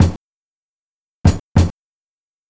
Track 14 - Kick Beat 01.wav